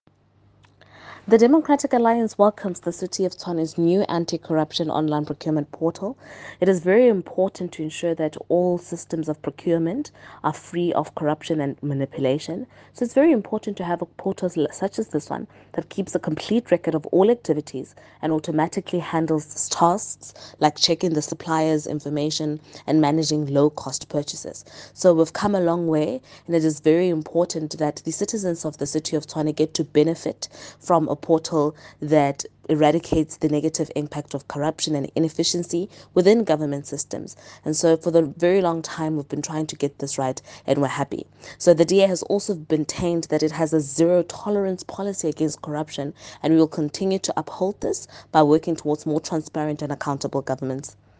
Note to editors: Please find attached soundbites in
Sesotho by Karabo Khakhau MP